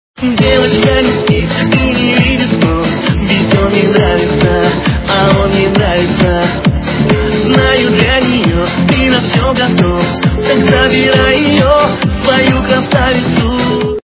качество понижено и присутствуют гудки.